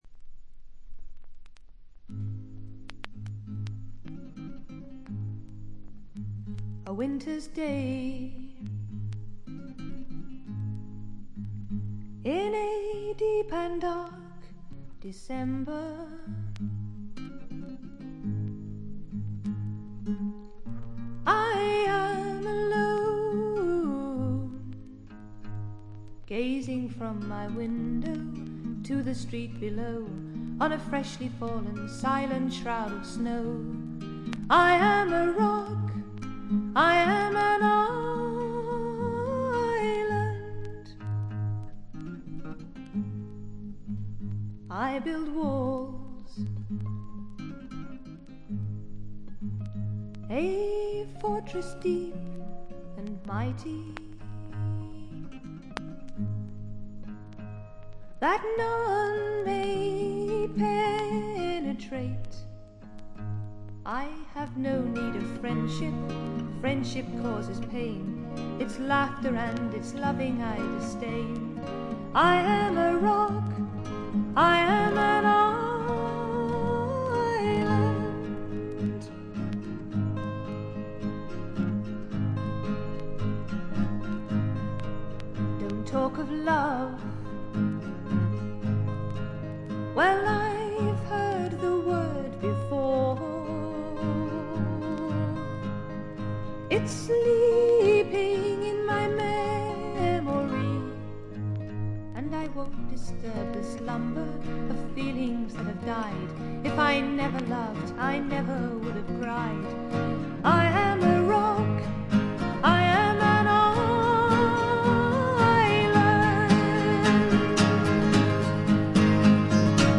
静音部でバックグラウンドノイズ、ところどころでチリプチ、散発的なプツ音少し。
試聴曲は現品からの取り込み音源です。